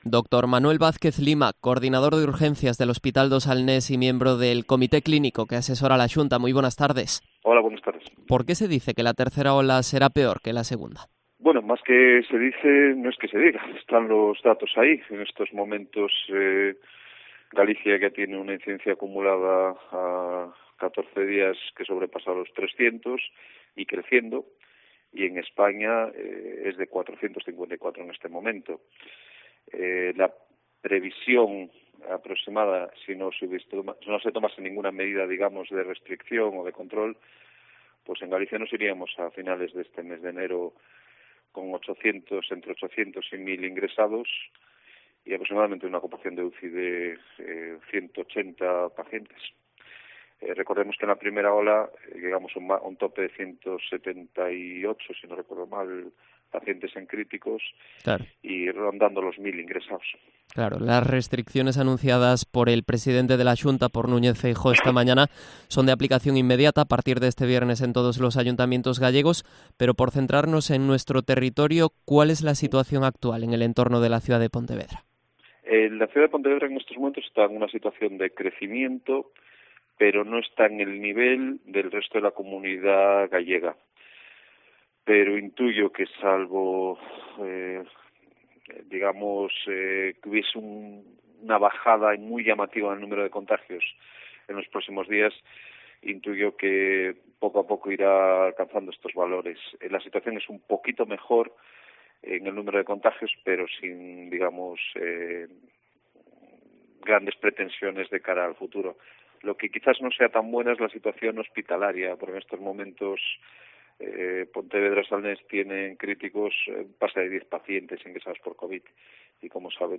Entrevista al miembro del Comité Clínico